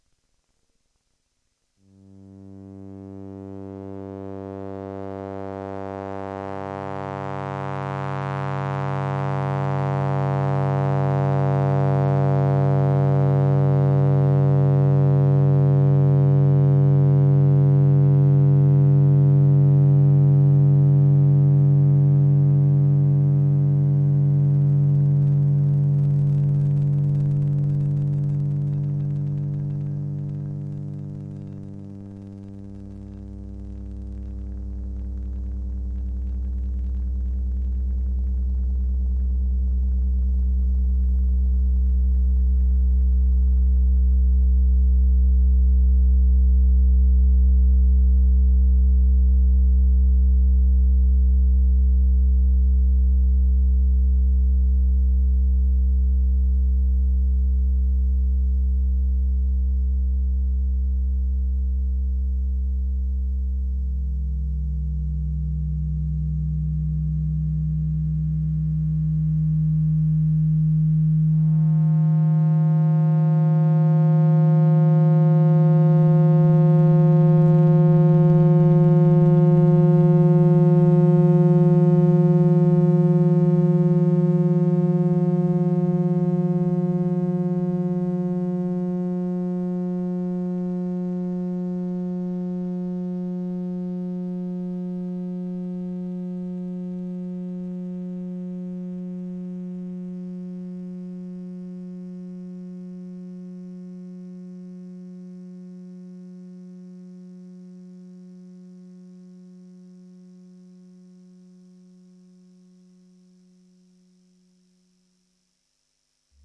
它是完全手工制作的，由一堆模拟电路组成，通电后会产生奇怪的电流振荡。它也被内置在一个Seseame Street玩具萨克斯风中。一个长长的奇怪的声音。
标签： 模拟电路弯曲 无人机 噪声 合成器
声道立体声